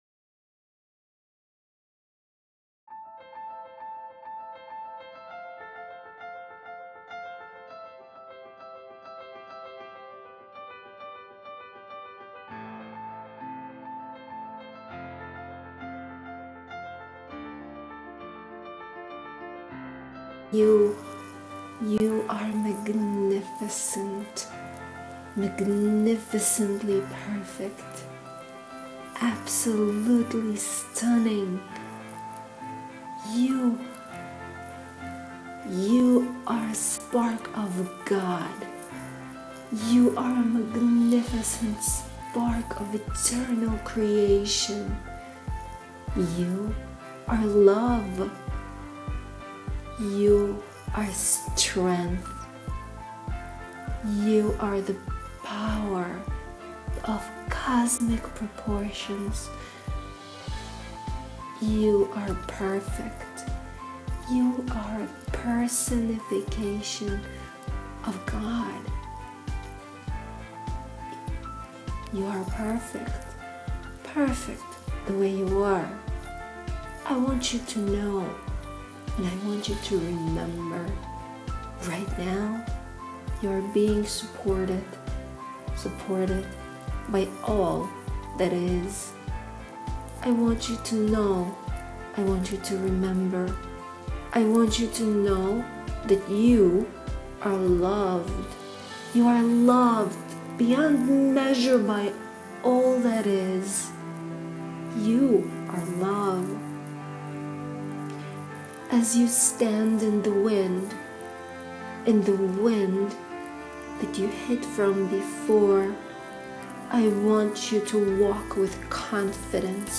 YOUI have received this transmission from my Higher Self during the Avalanche portal. Forgive the sound quality as I recorded it live as it came.